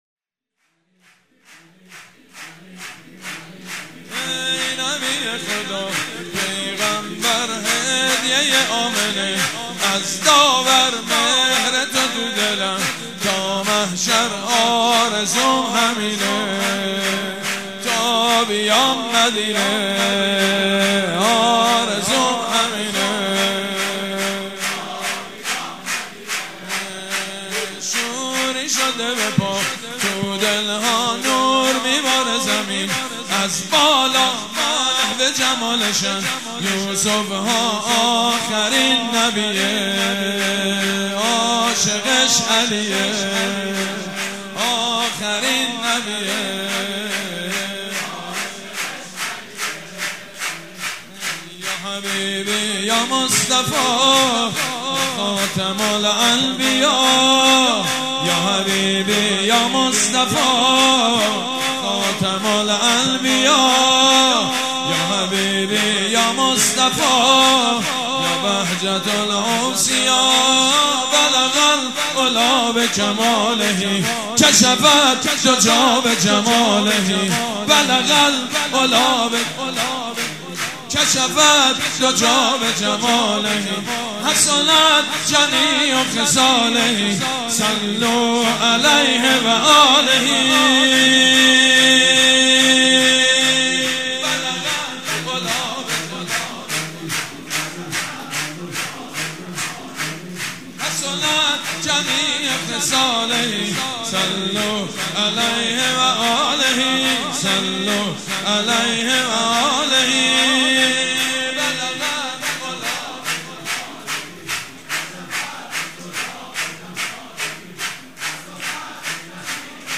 هيئت ريحانة الحسين(س)
شور
مداح
حاج سید مجید بنی فاطمه
ولادت حضرت محمد (ص) و امام صادق (ع)